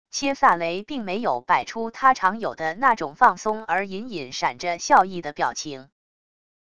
切萨雷并没有摆出他常有的那种放松而隐隐闪着笑意的表情wav音频生成系统WAV Audio Player